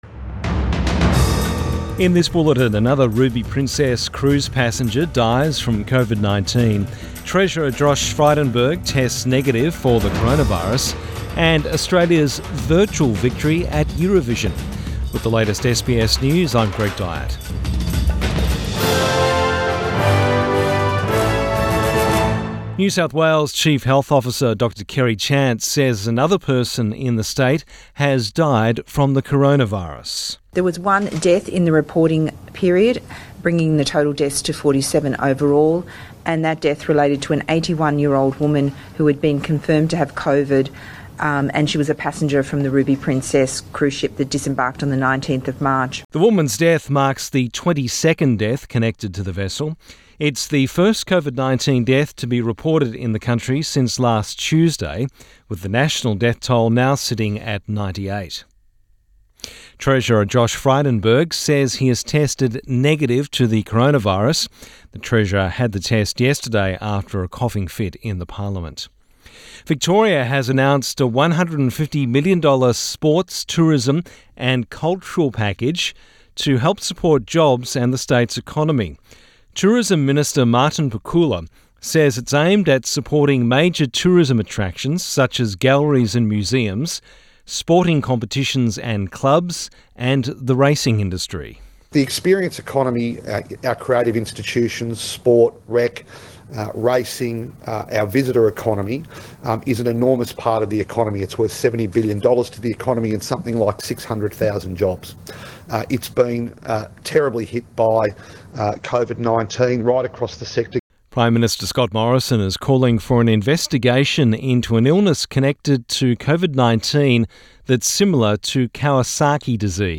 Midday bulletin 13 May 2020